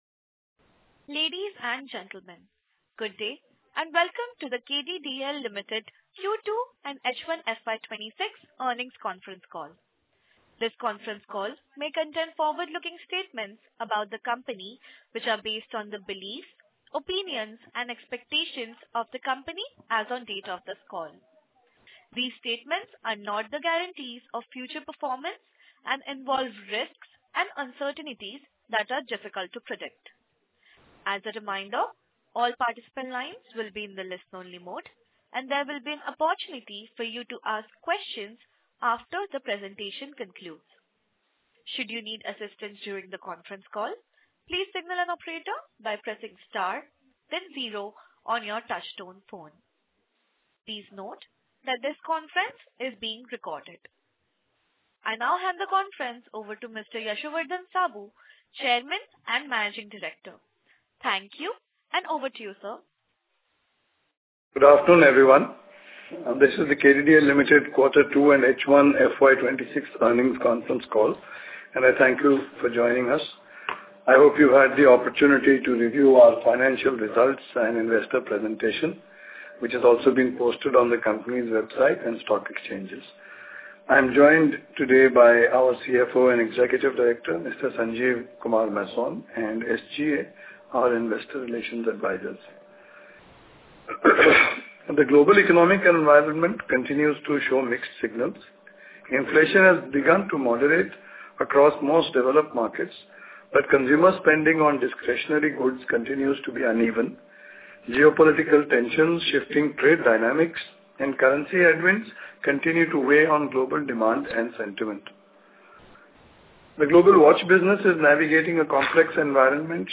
Q4FY24 Earnings Call Audio Recording